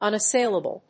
音節un・as・sail・a・ble 発音記号・読み方
/`ʌnəséɪləbl(米国英語), ˌʌnʌˈseɪlʌbʌl(英国英語)/